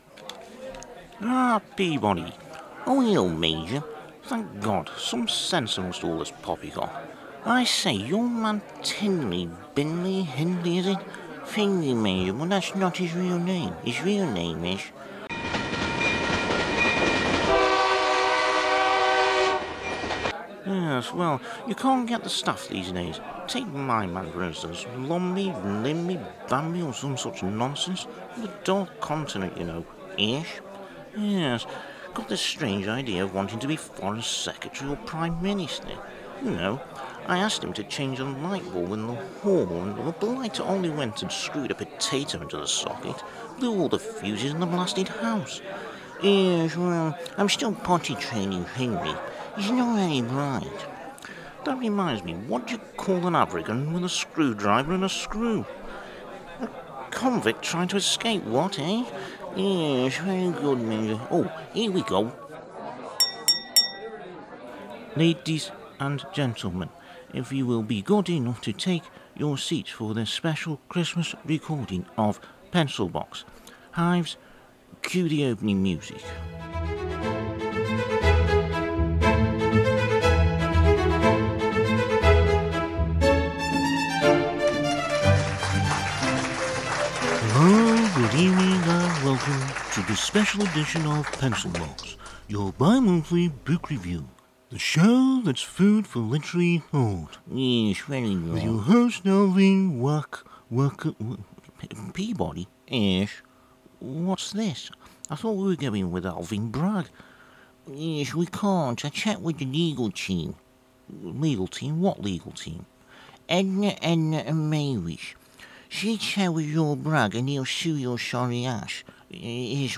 Only 8 minutes long so more of a comedy sketch.